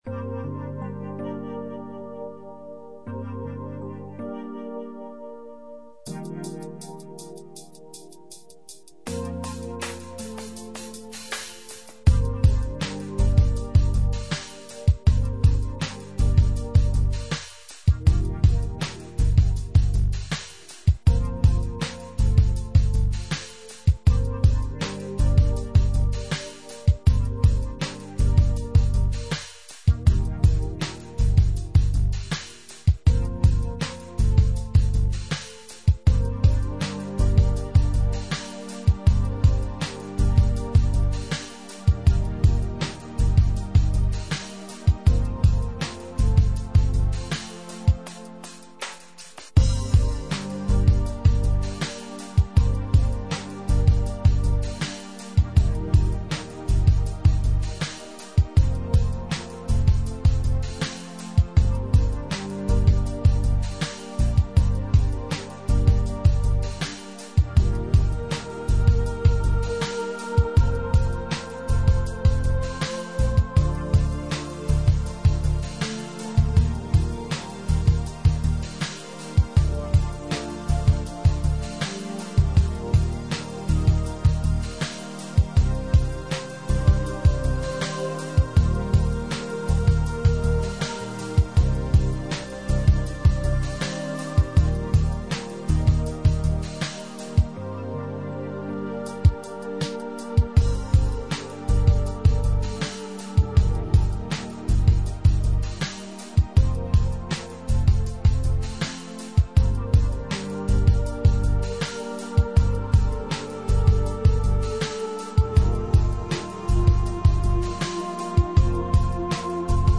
dance/electronic
Ambient